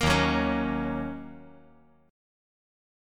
D#m7 Chord
Listen to D#m7 strummed